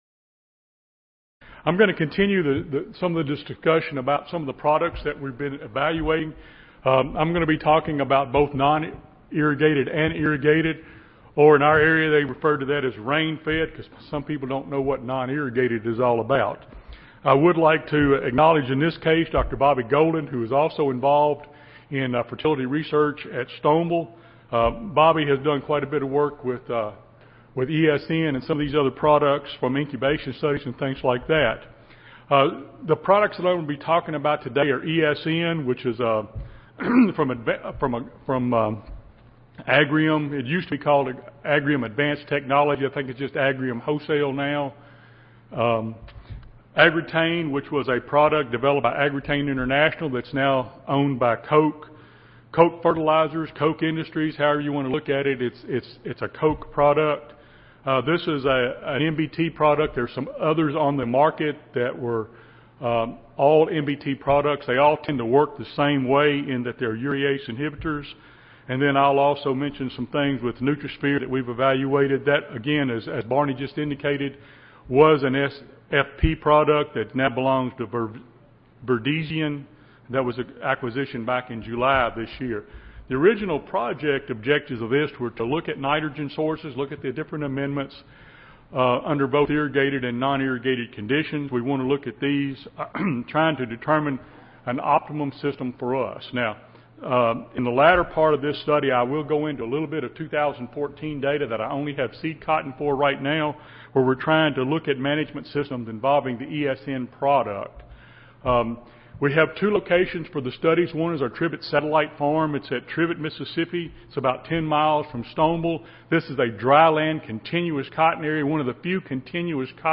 Recorded Presentation Alternative strategies for managing nitrogen (N) for cotton production under rain-fed and irrigated conditions continue in the Mid-South.